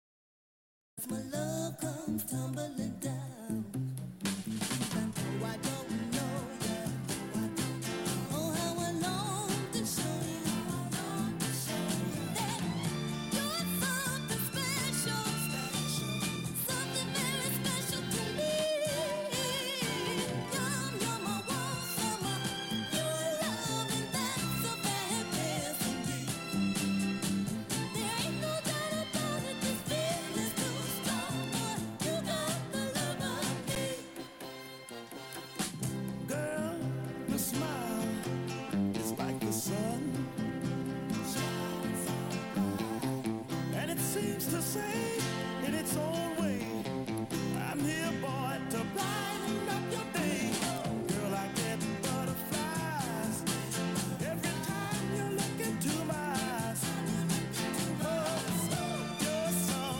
psychedelic soul